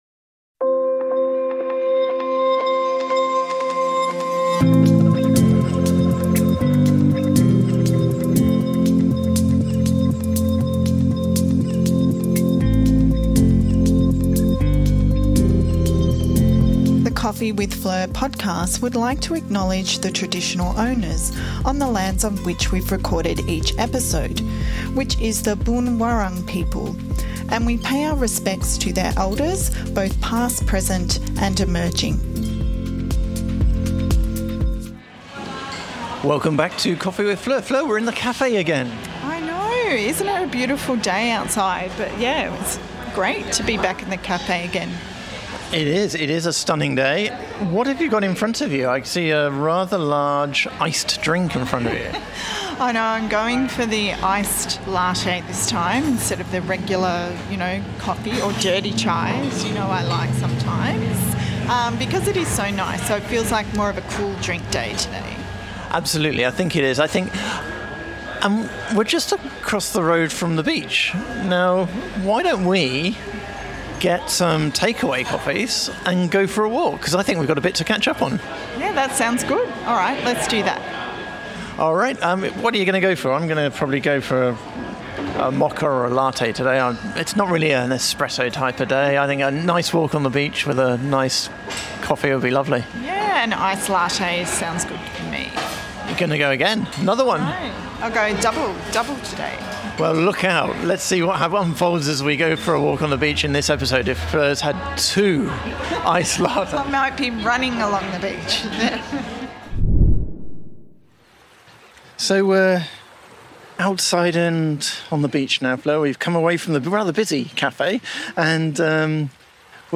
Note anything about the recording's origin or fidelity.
We reflect on the episodes we’ve done, topics we’ve covered and fun we’ve had, all while walking along the beach with a coffee in hand.